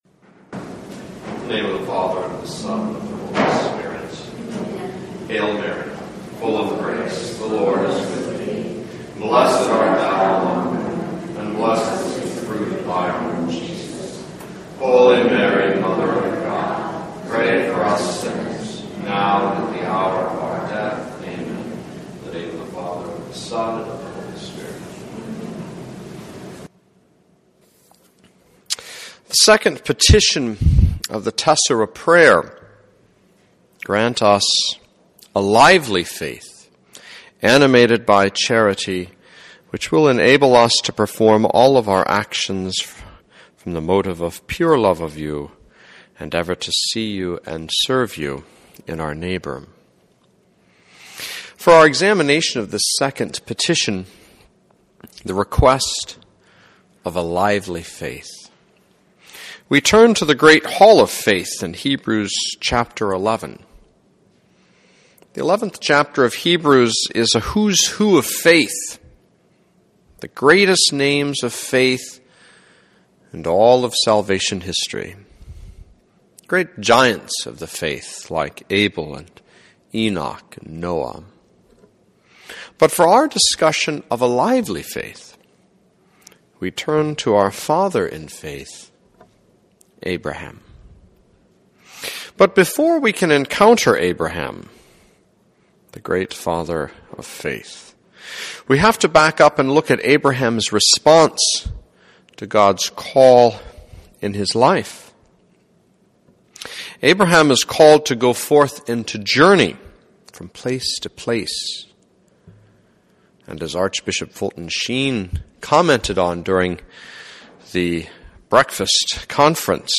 Our Lady of Good Counsel Retreat House, Waverly Nebraska